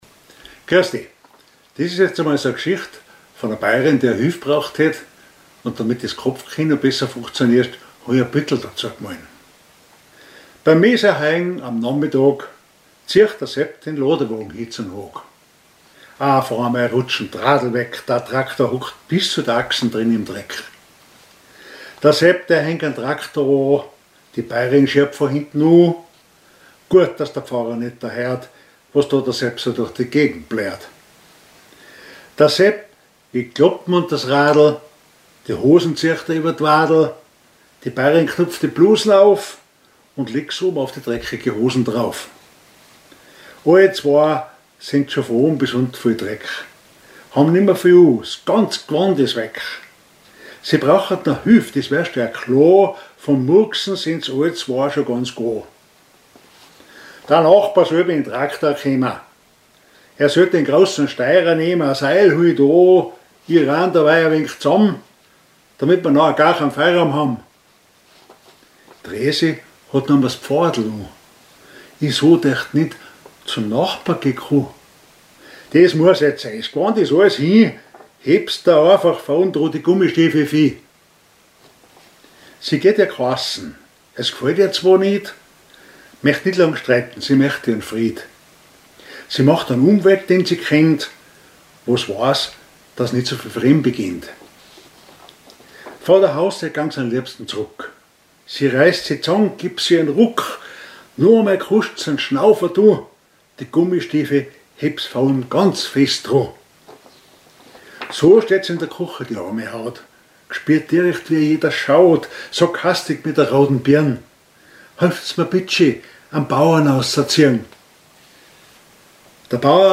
Gedicht Monat September 2025
Type: witzig